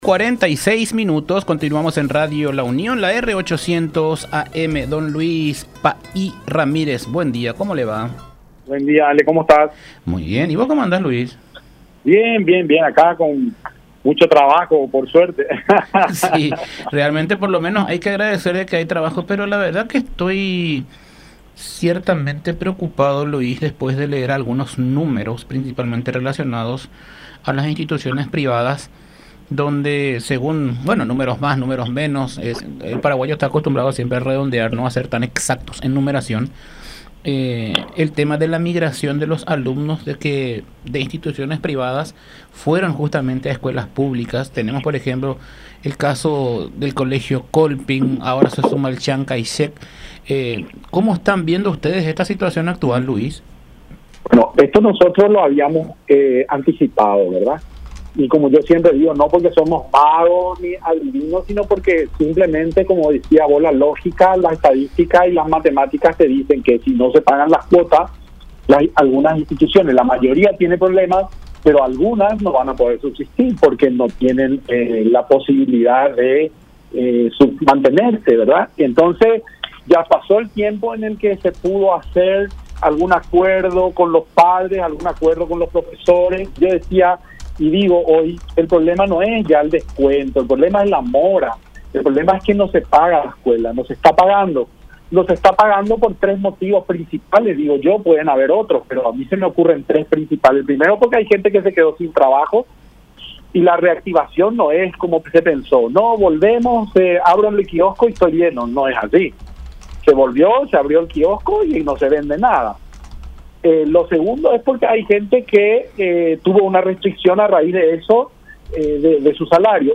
en diálogo con La Unión R800 AM